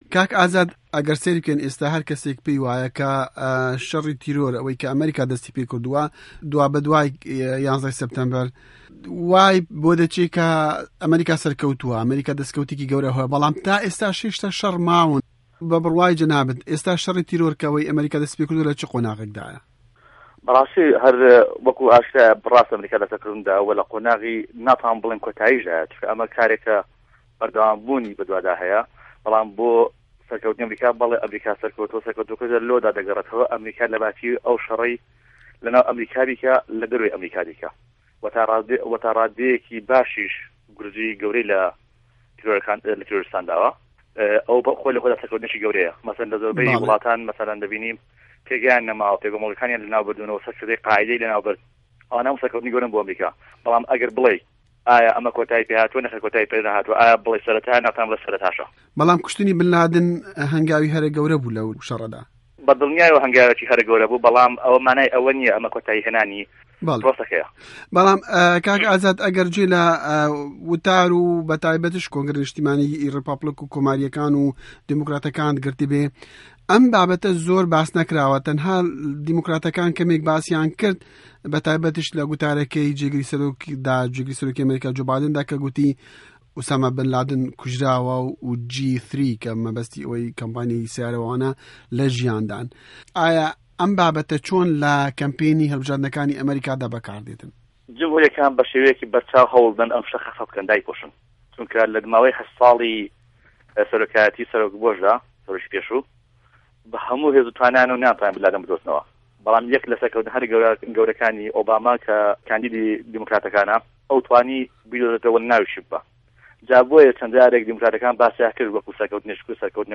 Hevpeyv'în